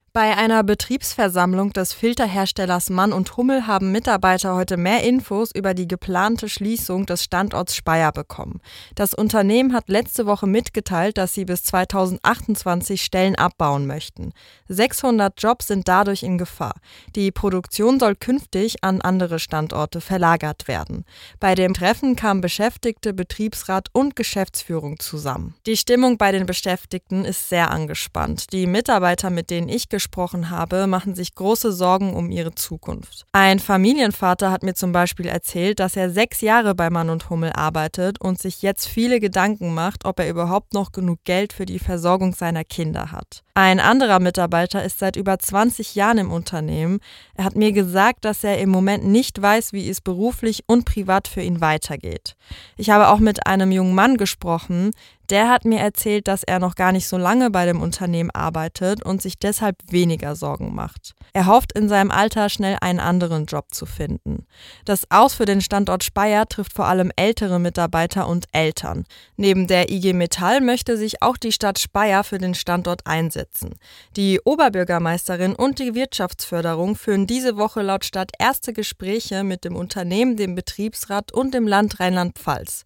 Wir haben mit Mitarbeitern gesprochen.